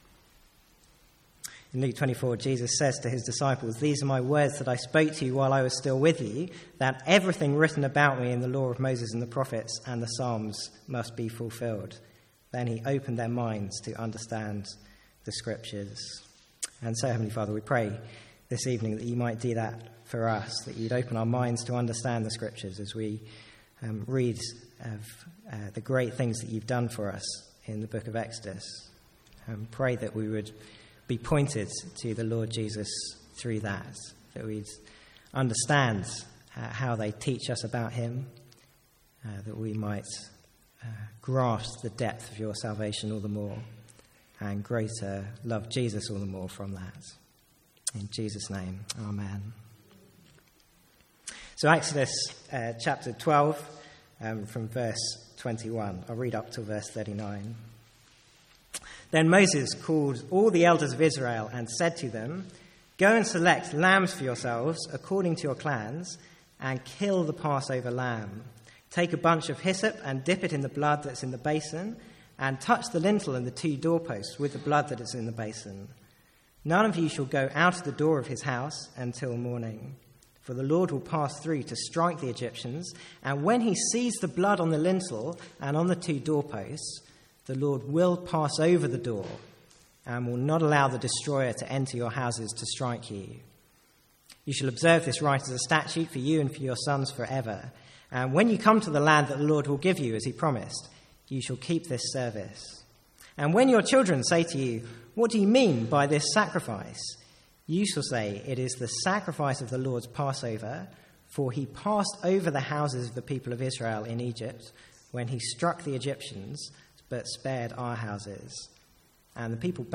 Sermons | St Andrews Free Church
From the Sunday evening series in Exodus.